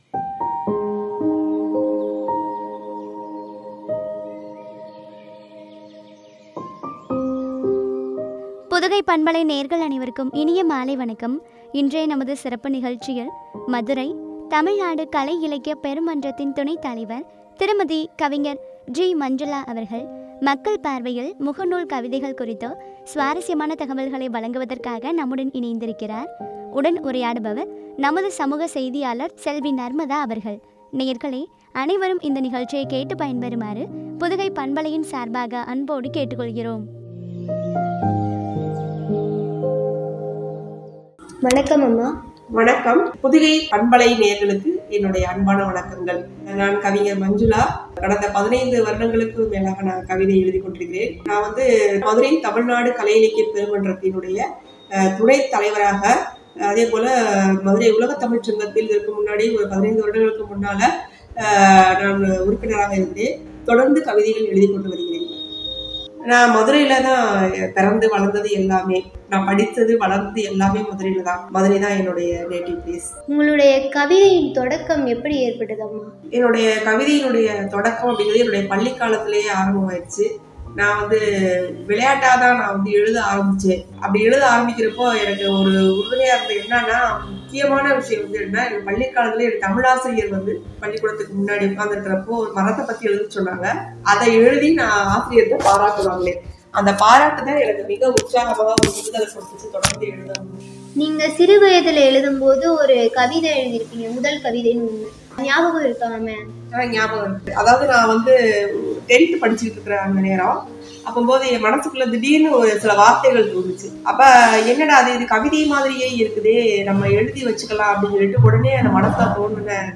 மக்கள் பார்வையில் முகநூல் கவிதைகள் பற்றிய உரையாடல்.